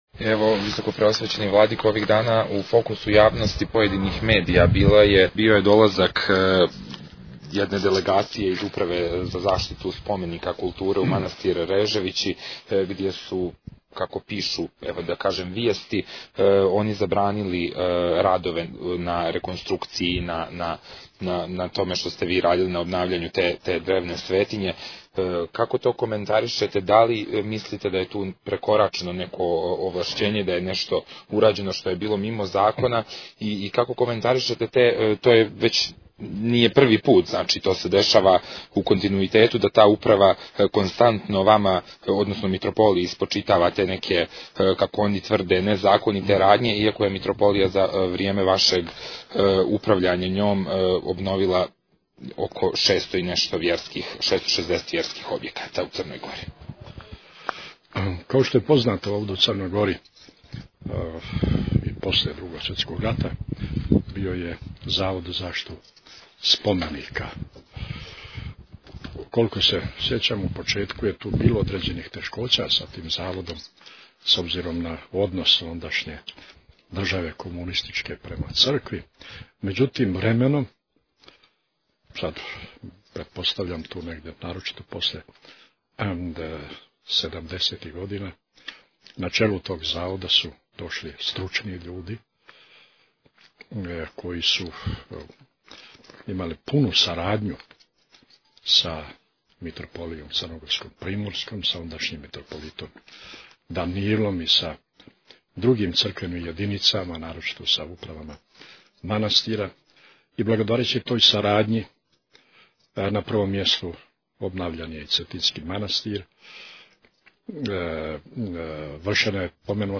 Интервју | Радио Светигора